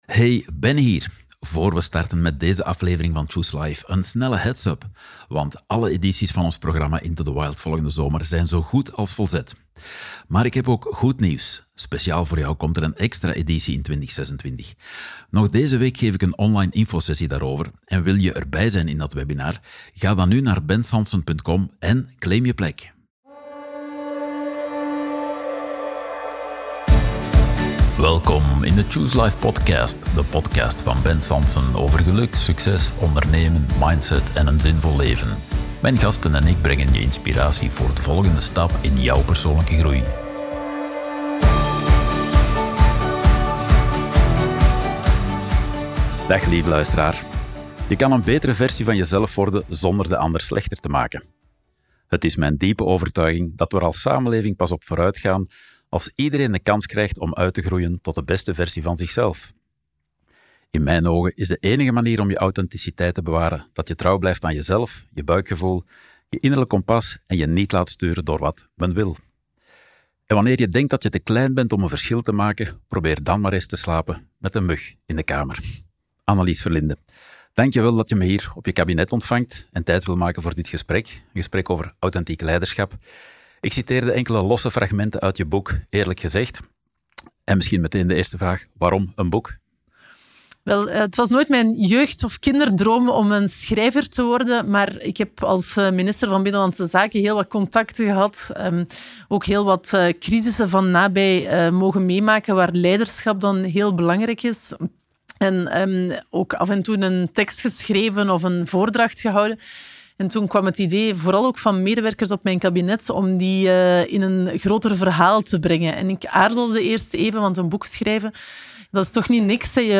Een bijzondere aflevering van Choose Life met minister Annelies Verlinden. Een openhartig gesprek over leiderschap, authenticiteit en de kracht van trouw blijven aan je innerlijk kompas.